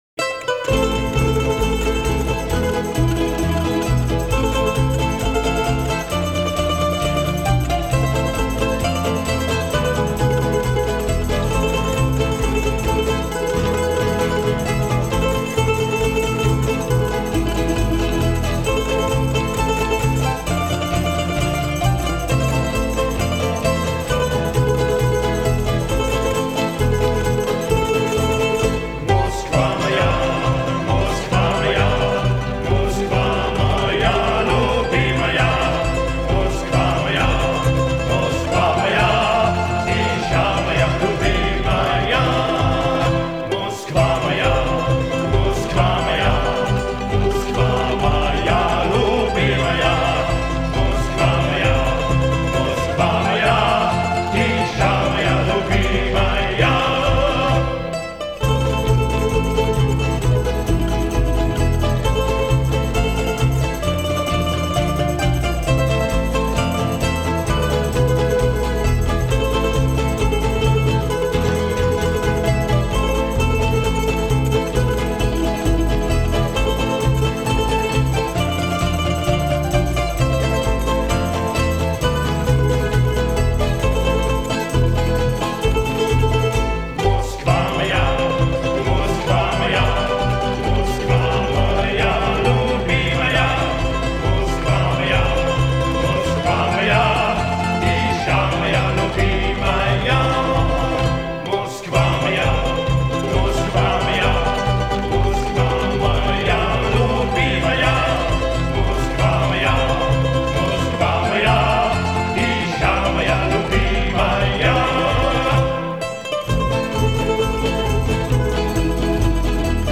Balalaika